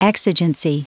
Prononciation du mot : exigency
exigency.wav